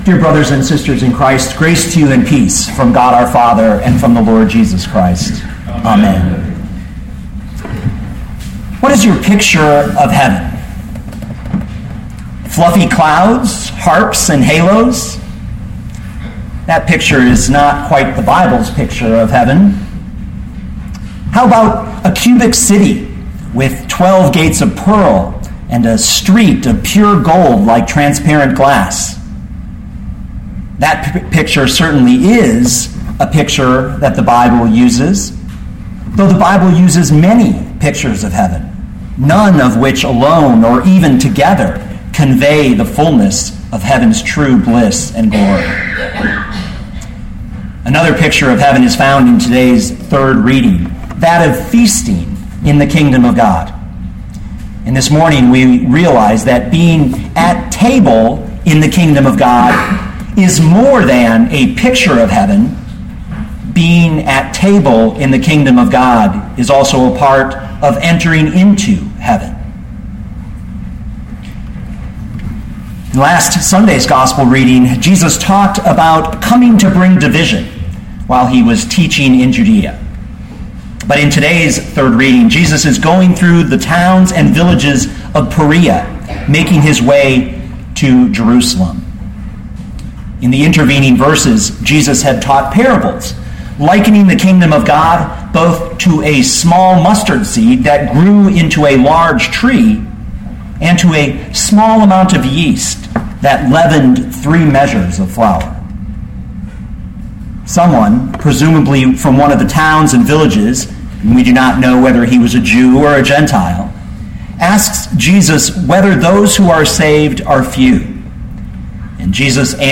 2013 Luke 13:22-30 Listen to the sermon with the player below, or, download the audio.